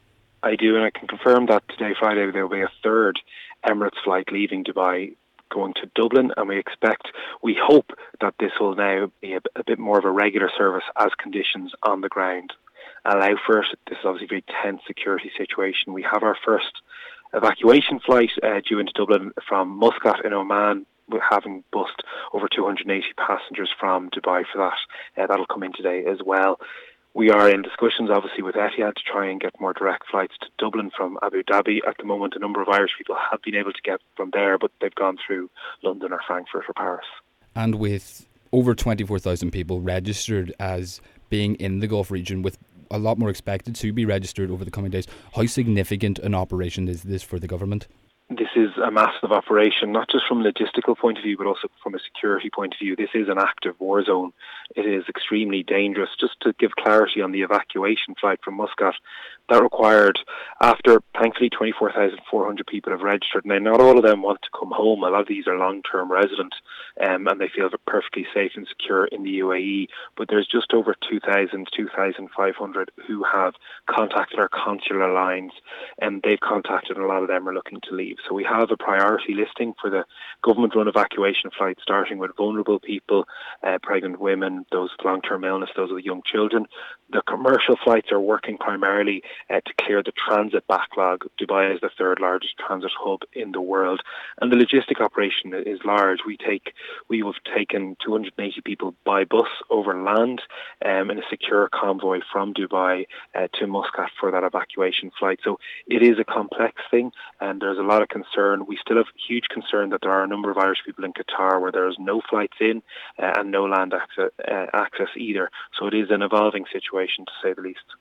Speaking to Highland Radio News, Minister of State for International Development and Diaspora, Neale Richmond, explains the logistics of this operation: